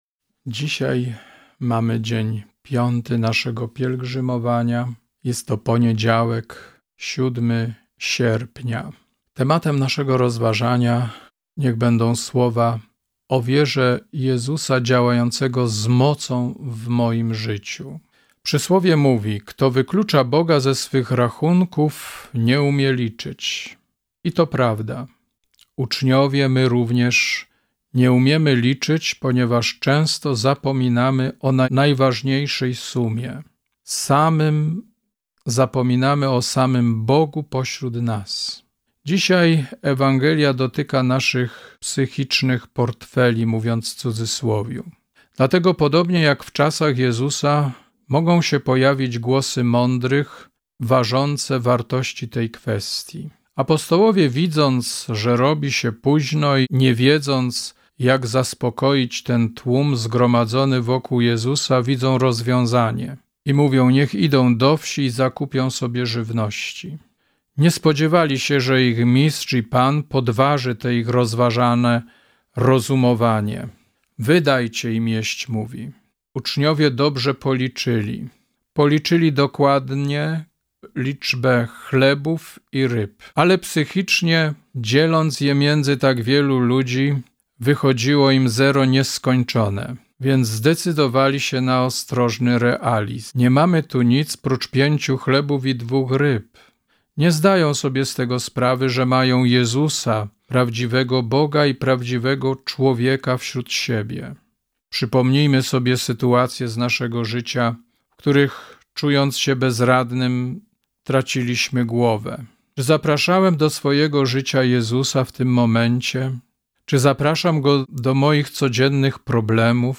#5 Rozważania Pielgrzymkowe